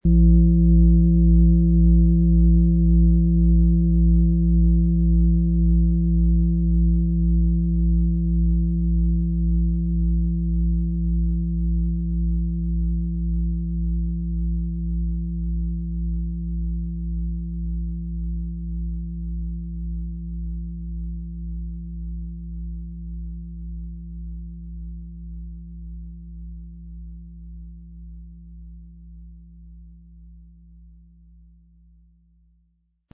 XXXL-Fußreflexzonen-Klangschale – tief berührende Schwingungen für pure Entspannung
Ihre harmonisch abgestimmten Schwingungen wirken tief, gleichmäßig und heilend – sie führen dich behutsam in deine innere Mitte.
Im Lieferumfang enthalten ist ein Schlegel, der die Schale wohlklingend und harmonisch zum Klingen und Schwingen bringt.
MaterialBronze